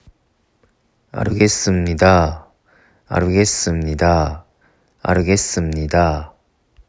了解の発音4連発
【アルゲッスンニダ】